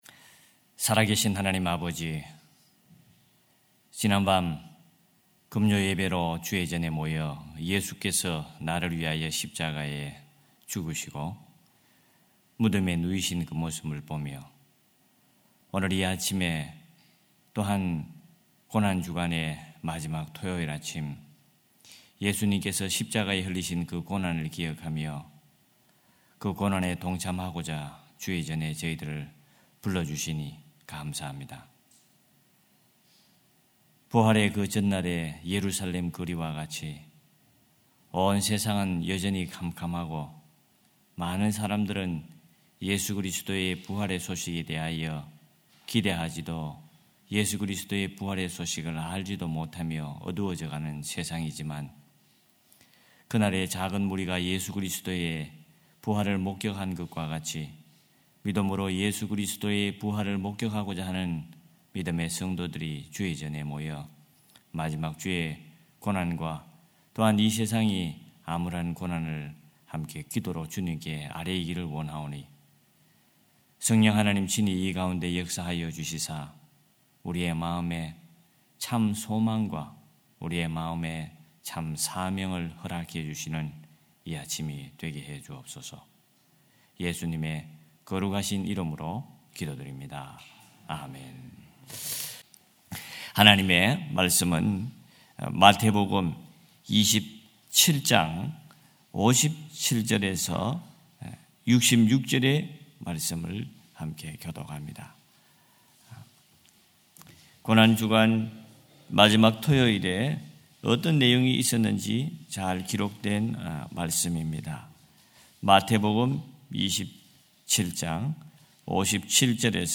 4.19.2025 새벽예배